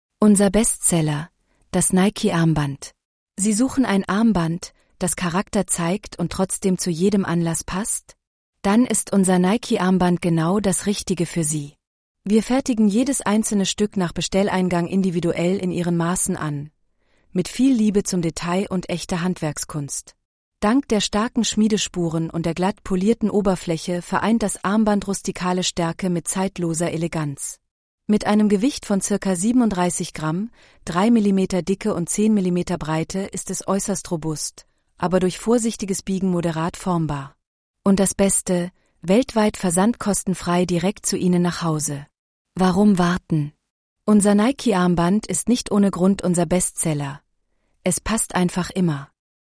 Nike-r-tts-reader.mp3